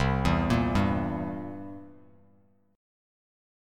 C7 Chord
Listen to C7 strummed